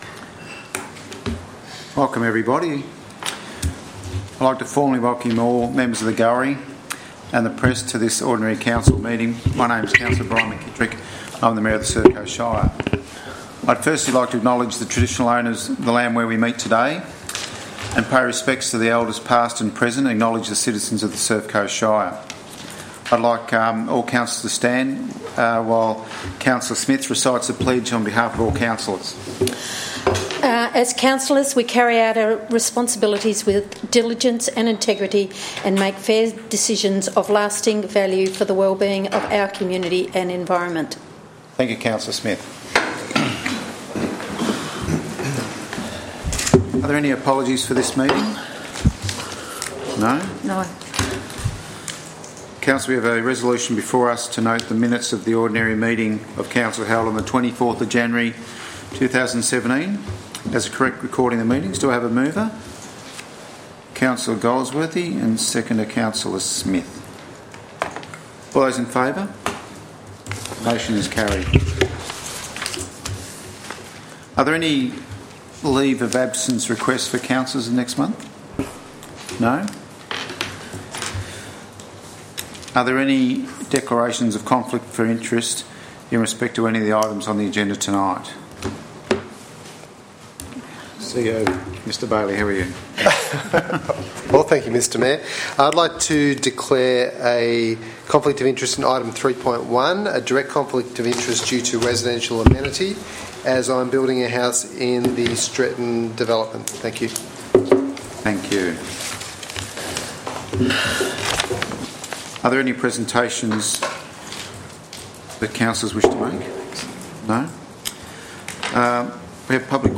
Audio-Recording-Ordinary-Council-Meeting-28-February-2017.mp3